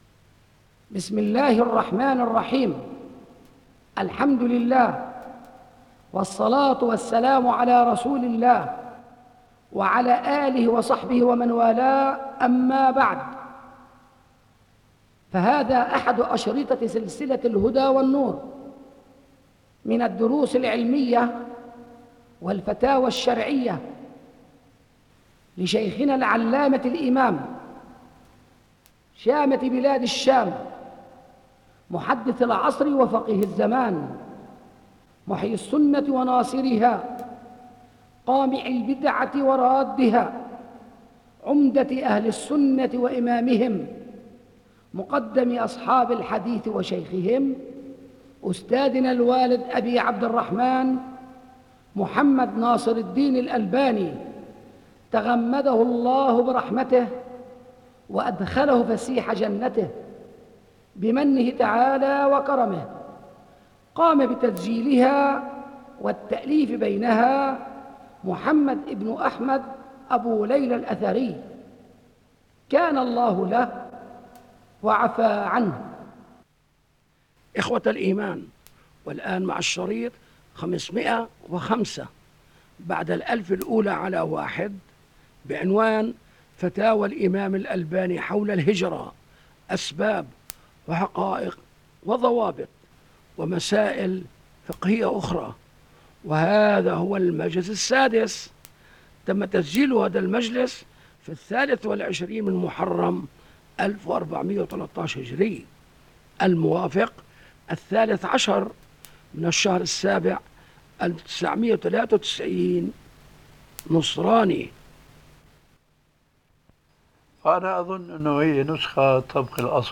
بيتُ الإمام الألباني
بِصوتِ الإِمامِ الألبَانِي